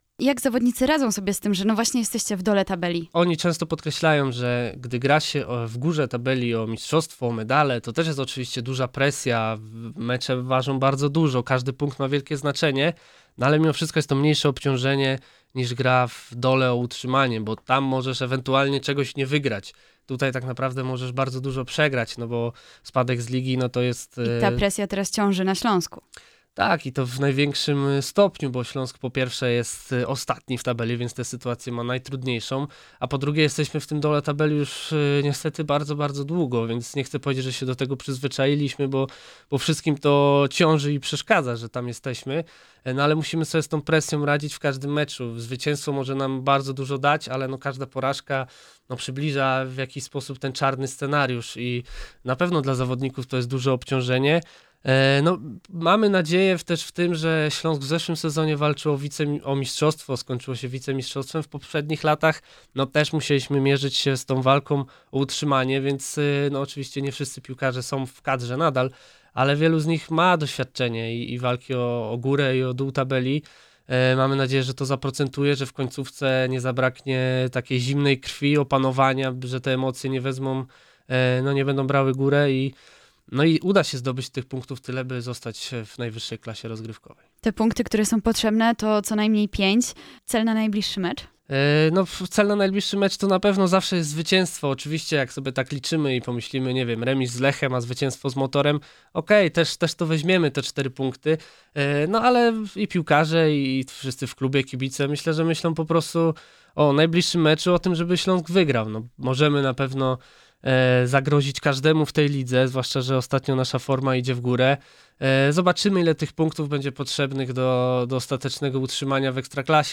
CAŁA ROZMOWA: